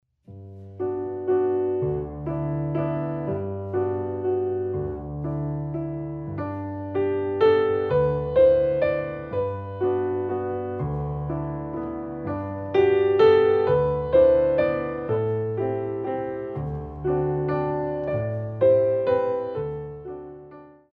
Waltz
Ballet Class Music For First Years of Ballet